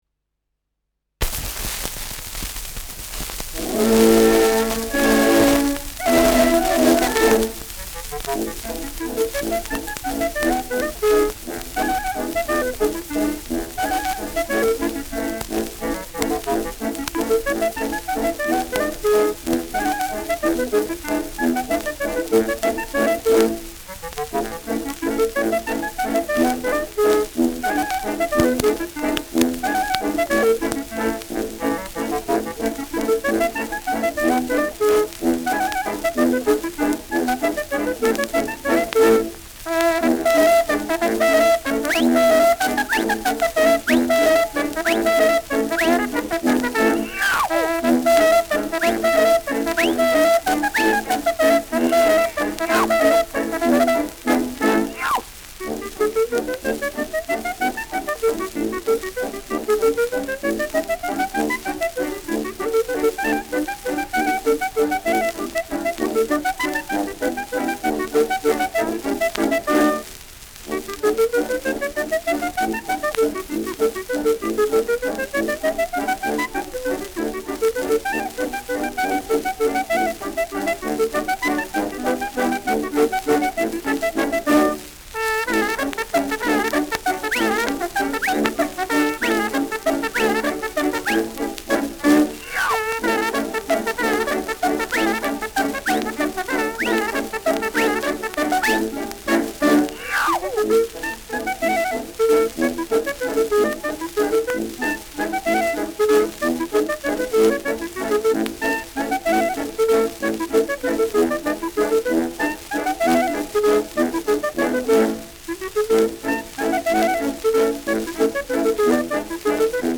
Schellackplatte
leichtes Rauschen : Knacken
Mit Juchzern, Pfiffen und Rufen.